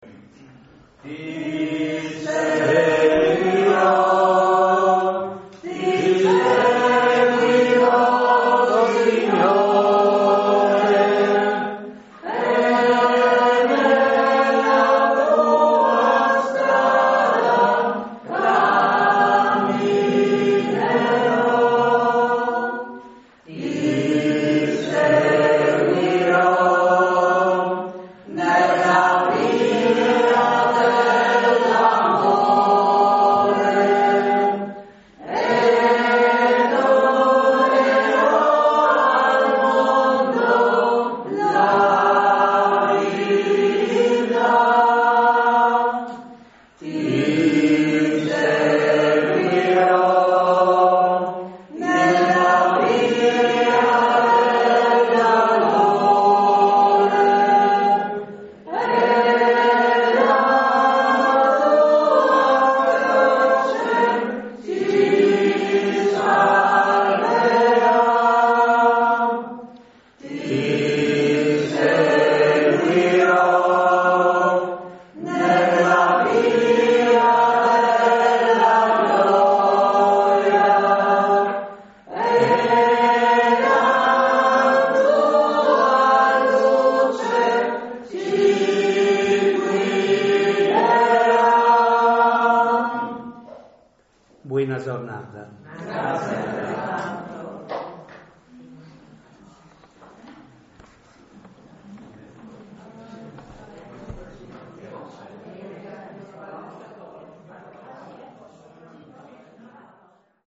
Santa Messa nella chiesetta di San Lorenzo
BENEDIZIONE E CANTO DI CHIUSURA